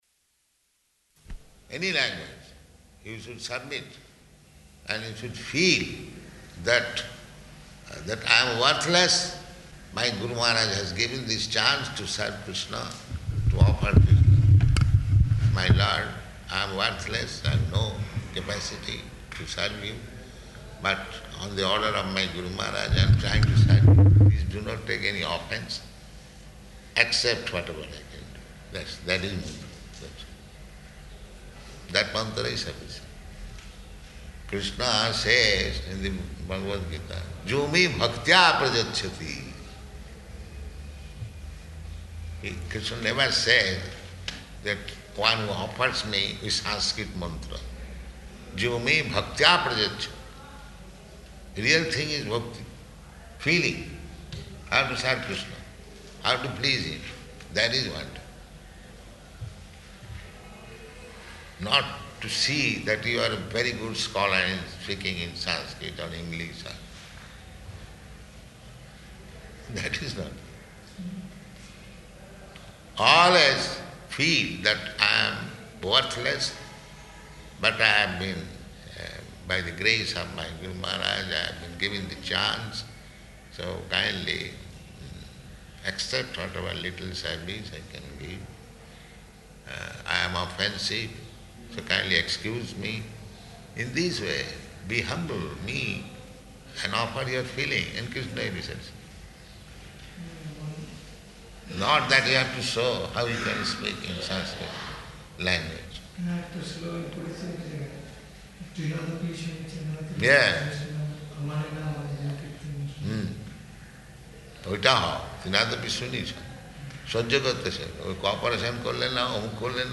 Room Conversation
Room Conversation --:-- --:-- Type: Conversation Dated: April 12th 1975 Location: Hyderabad Audio file: 750412R2.HYD.mp3 Prabhupāda: ...any language, you should submit, and you should feel that, that "I am worthless.